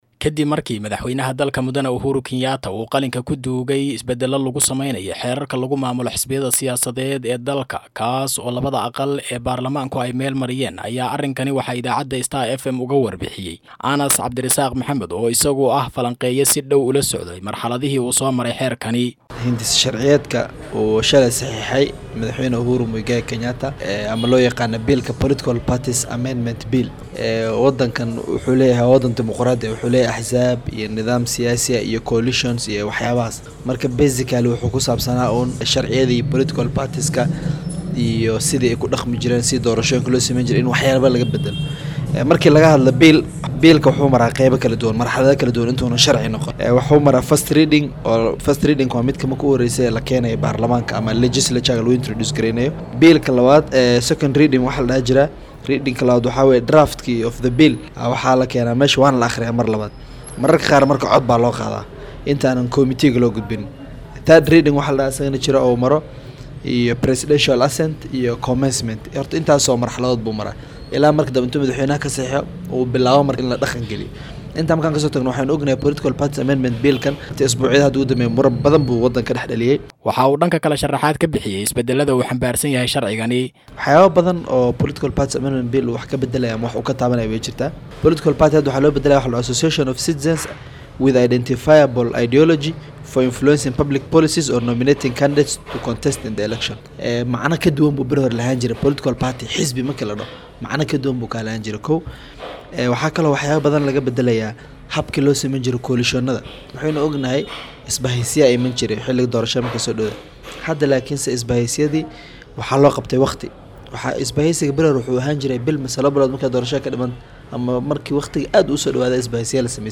oo arrimahan ka wareystay falanqeeye siyaasadeed